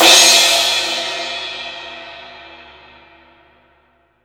Index of /90_sSampleCDs/East Collexion - Drum 1 Dry/Partition C/VOLUME 001
CRASH001.wav